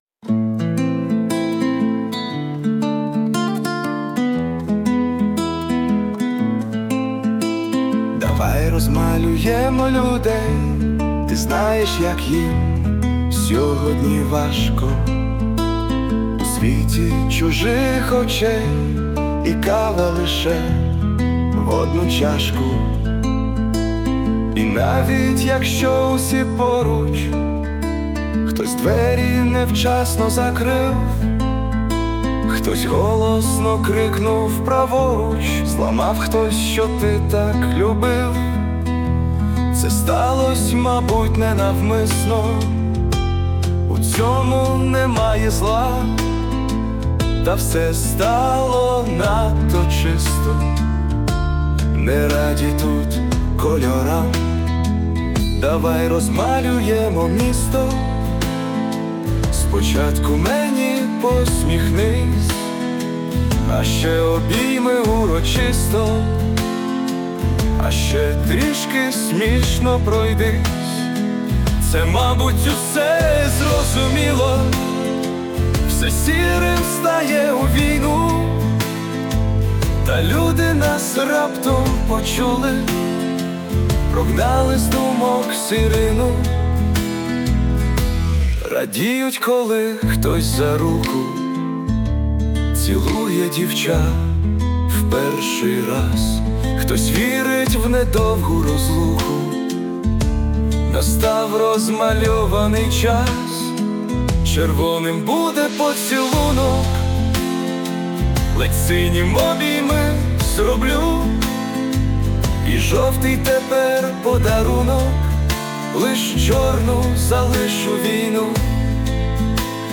Музичне прочитання з допомогою ШІ
зійшлися на мінімалізмі